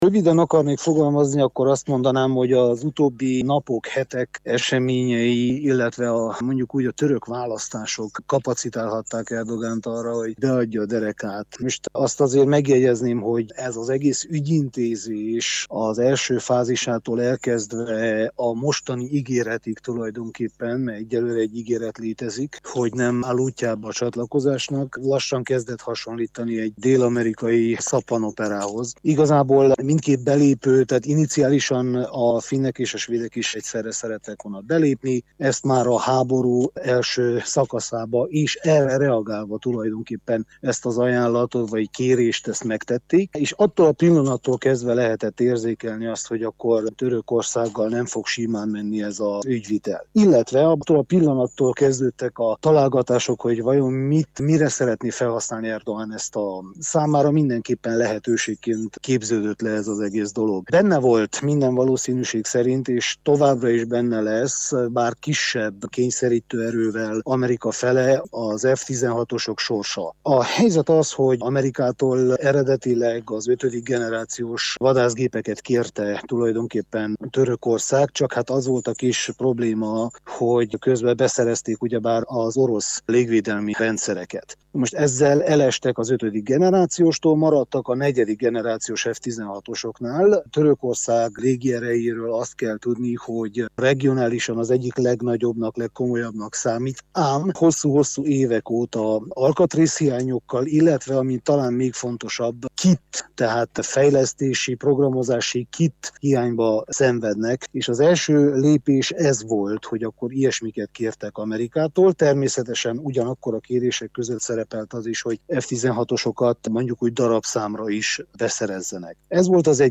újságíró, külpolitikai elemző